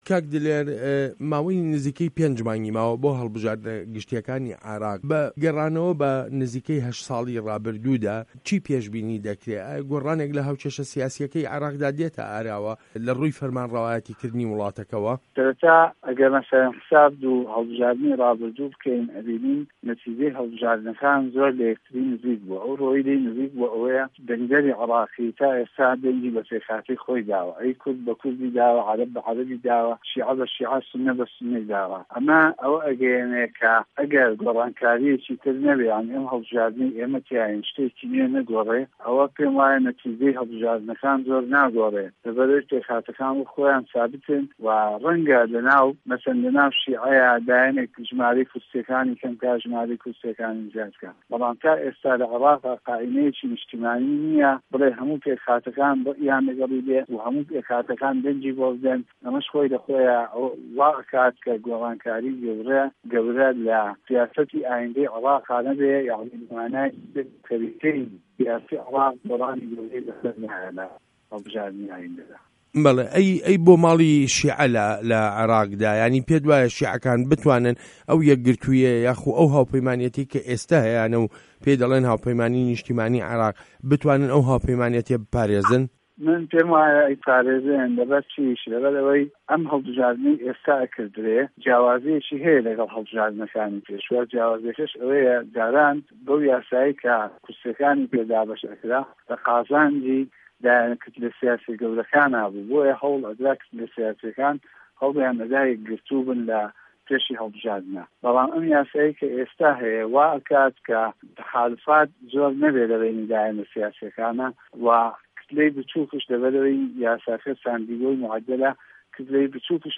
وتووێژ له‌گه‌ڵ دلێر عه‌بدولقادر